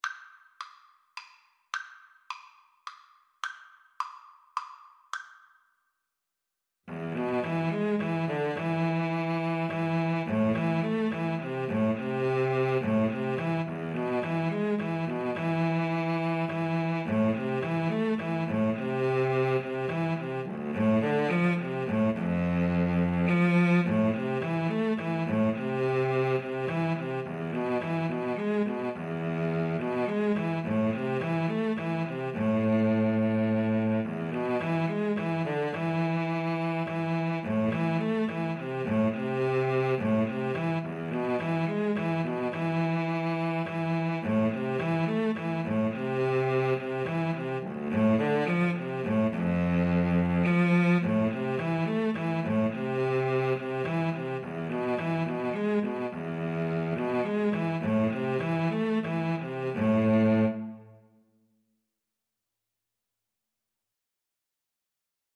3/4 (View more 3/4 Music)
Slow Waltz = c. 106